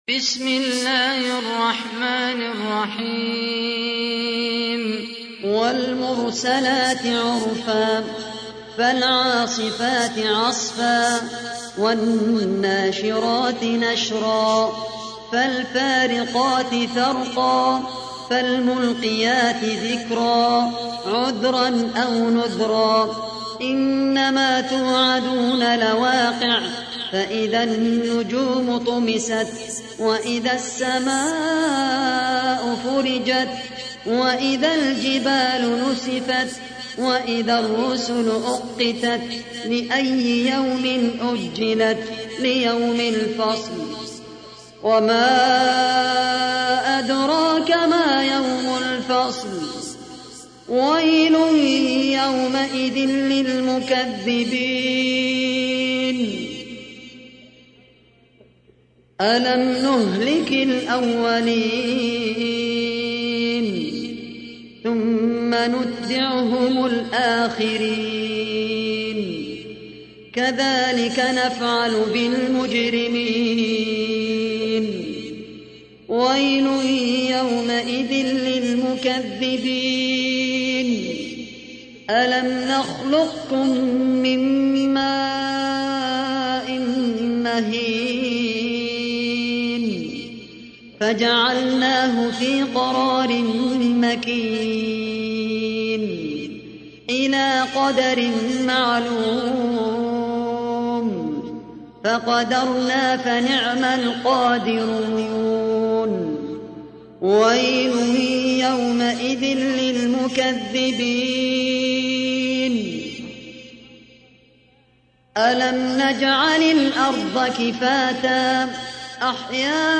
تحميل : 77. سورة المرسلات / القارئ خالد القحطاني / القرآن الكريم / موقع يا حسين